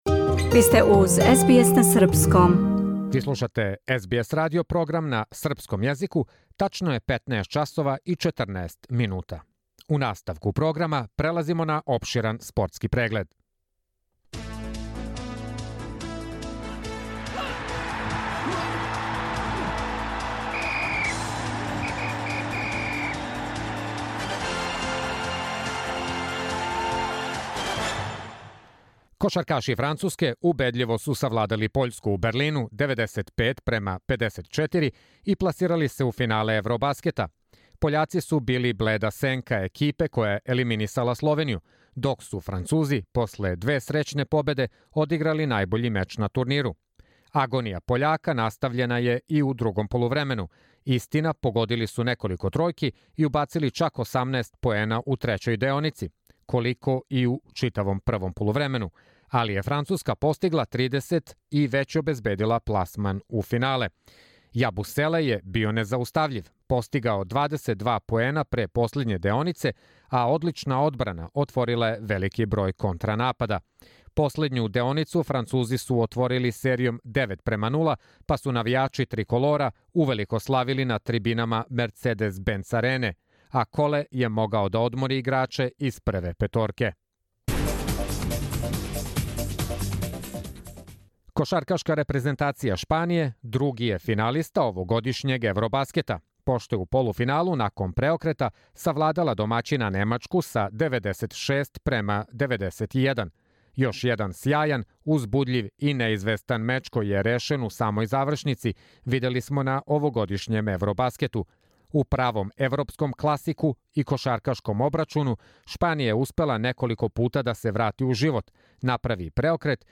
Спортски извештај